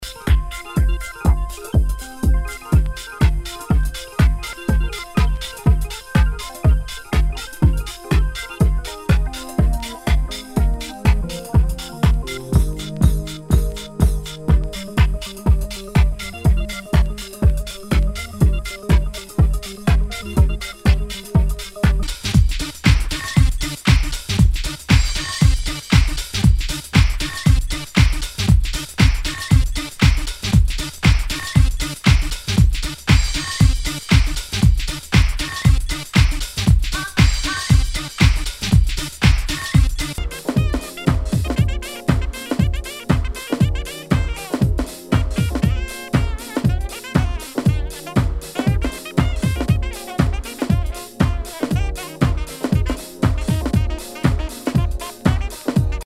HOUSE/TECHNO/ELECTRO
ナイス！ディープ・ハウス！